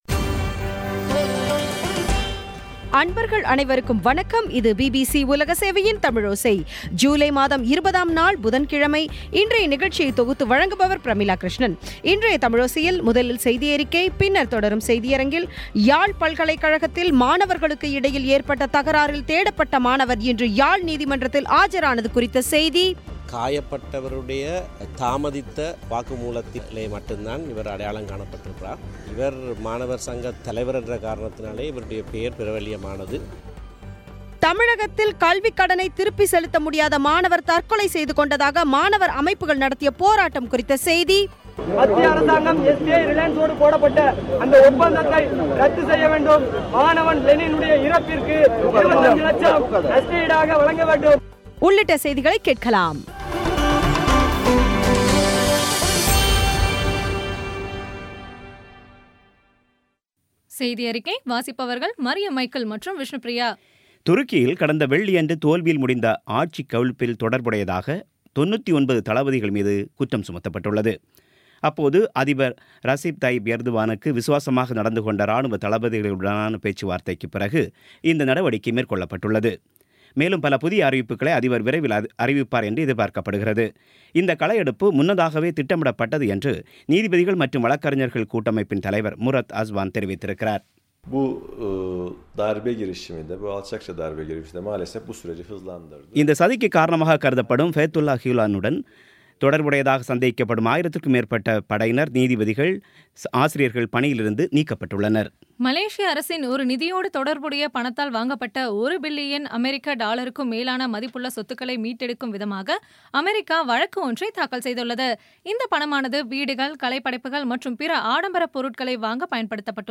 இன்றைய தமிழோசையில், முதலில் செய்தியறிக்கை பின்னர் தொடரும் செய்தியரங்கில், யாழ் பல்கலைக்கழகத்தில் மாணவர்களுக்கு இடையில் ஏற்பட்ட தகராறில் தேடப்பட்ட மாணவர் இன்று யாழ் நீதிமன்றத்தில் ஆஜரானது குறித்த செய்தி தமிழகத்தில் கல்விக்கடனை திருப்பி செலுத்த முடியாத மாணவர் தற்கொலை செய்துகொண்டதாக மாணவர் அமைப்புகள் நடத்திய போராட்டம் குறித்த செய்தி ஆகியவை கேட்கலாம்